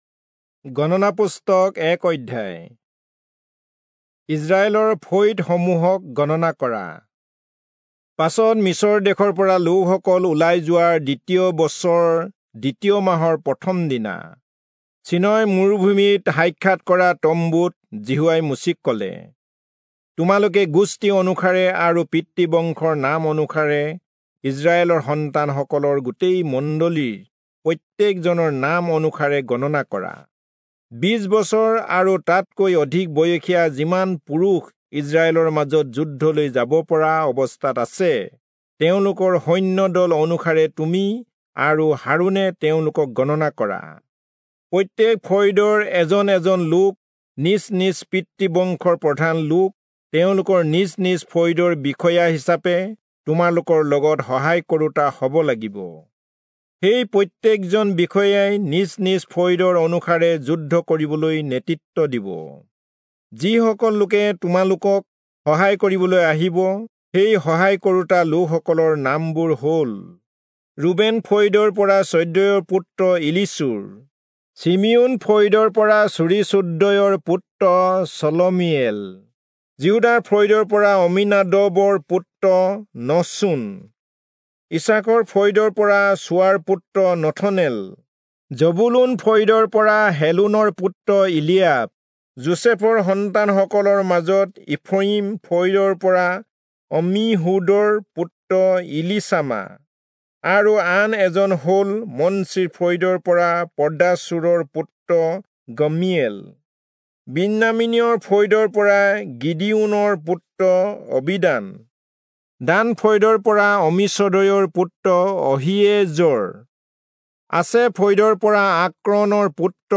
Assamese Audio Bible - Numbers 6 in Ervmr bible version